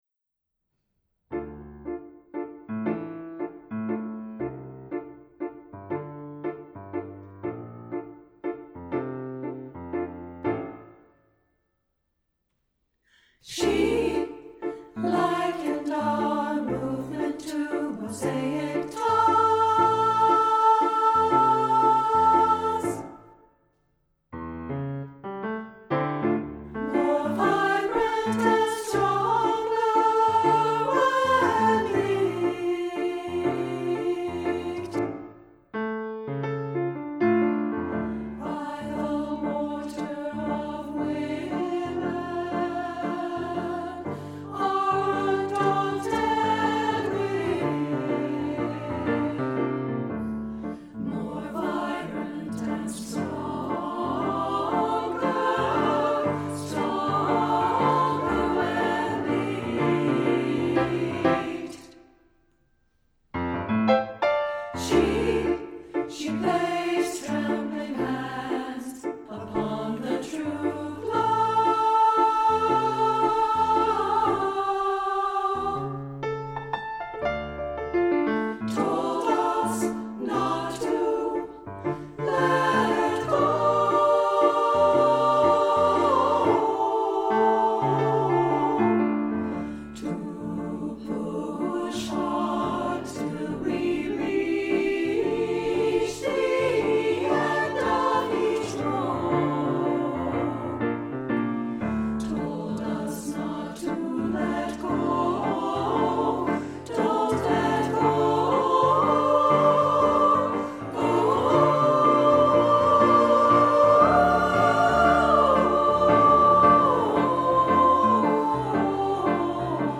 SSA choir, piano, opt. alto sax solo